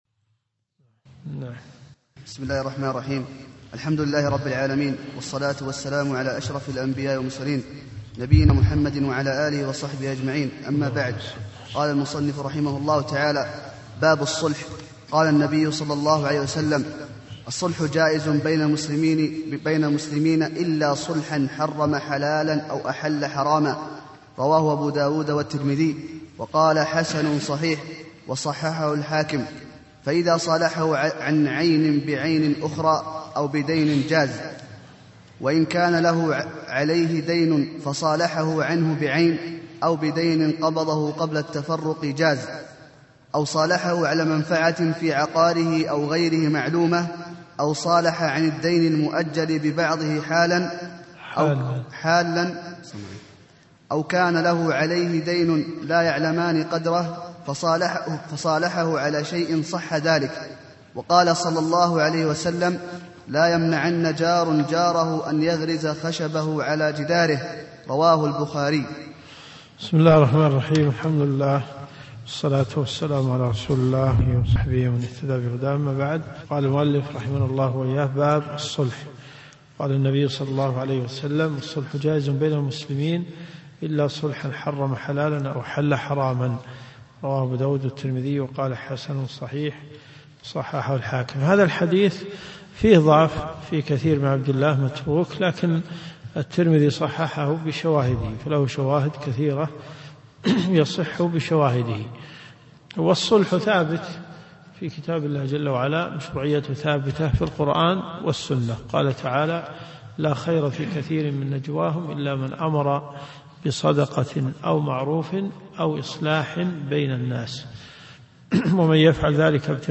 منهج السالكين . كتاب البيوع . من ص 68 باب الصلح -إلى-ص 73 قوله قبل أن يجف عرقه . المدينة المنورة . جامع البلوي
الرئيسية الدورات الشرعية [ قسم الفقه ] > منهج السالكين . 1427 إلى 1430 .